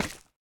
resin_step1.ogg